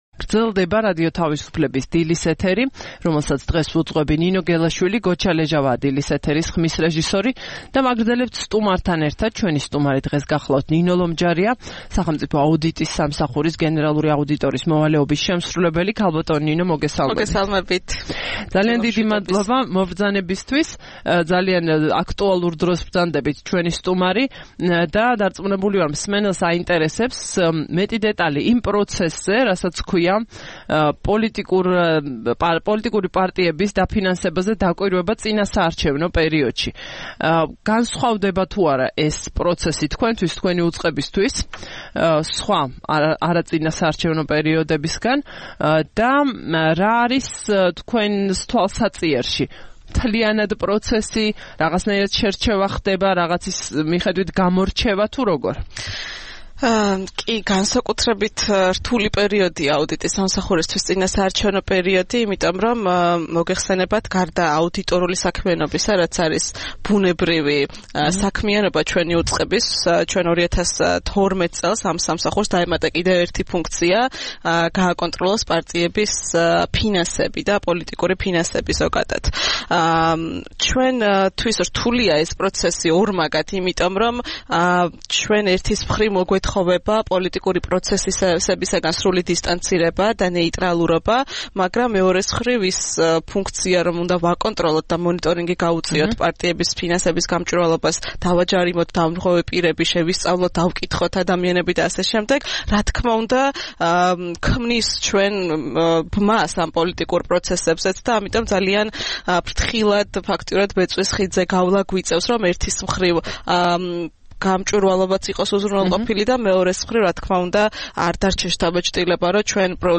14 სექტემბერს რადიო თავისუფლების "დილის საუბრების" სტუმარი იყო ნინო ლომჯარია, სახელმწიფო აუდიტის სამსახურის გენერალური აუდიტორის მოვალეობის შემსრულებელი.